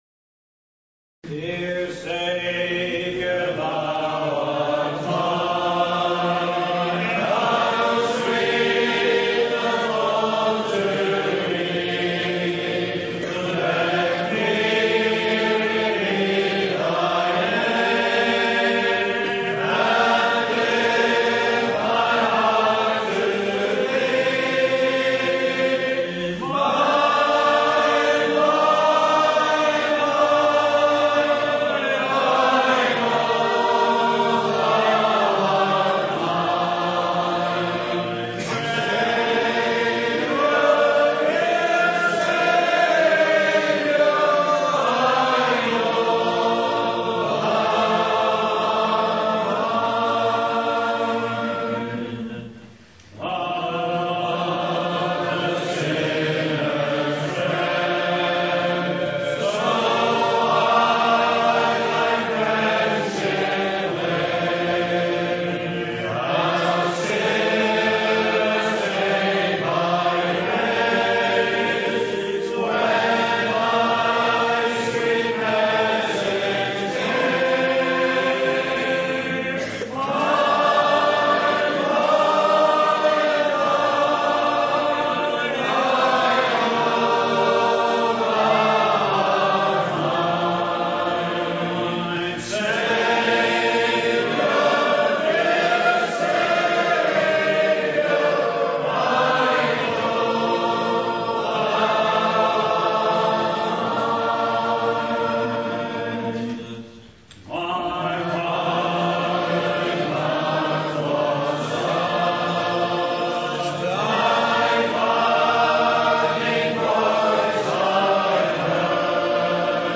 2012 Easter Conference Hymn Singing (Part 2/3)
PART 2 – 2012 Easter Conference Hymn Singing (45 mins):